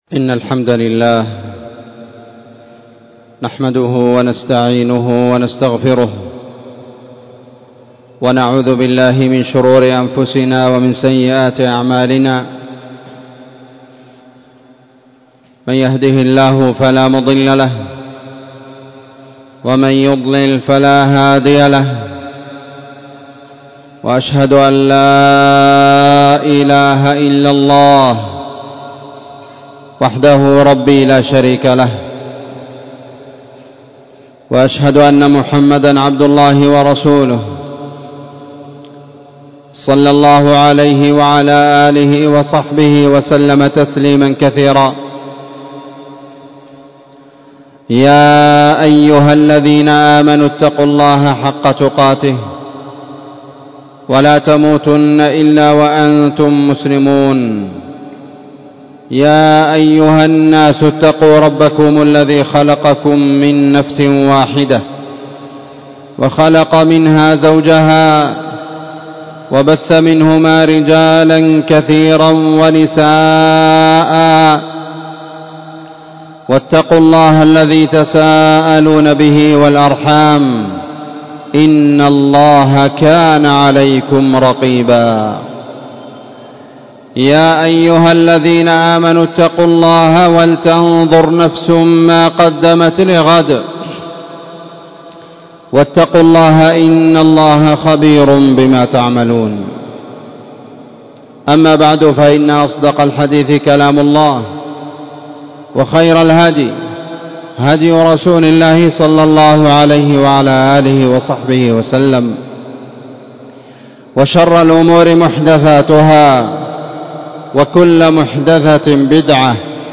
خطبة جمعة
مسجد المجاهد-النسيرية-تعز